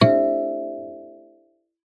kalimba2_wood-E4-ff.wav